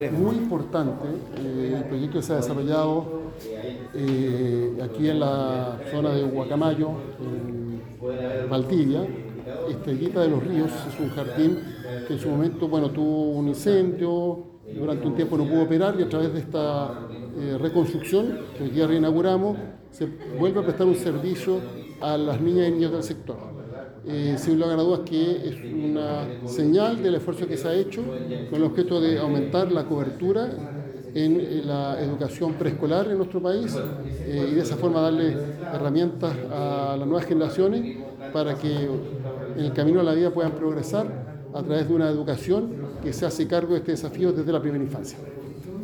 Ministro-del-Interior-Alvaro-Elizaldelizalde-mp3cut.net1_.mp3